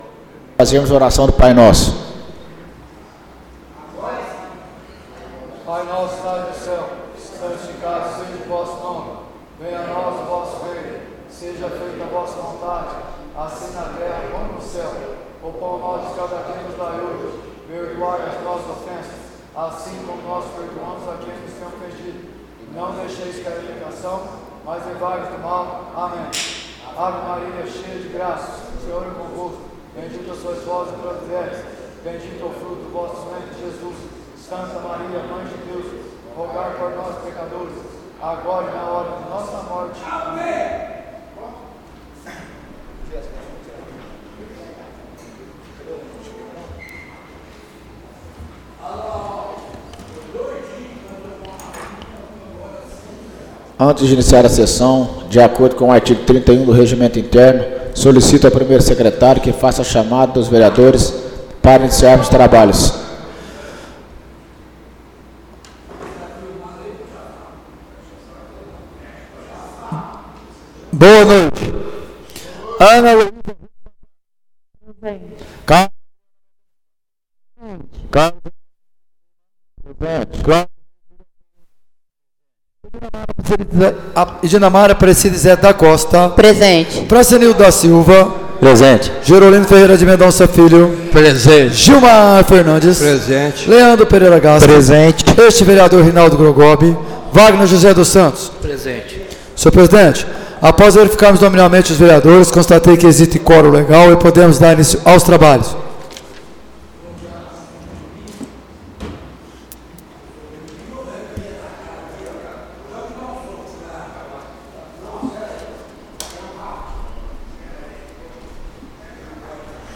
Áudio da Sessão Ordinária de 07/10/2024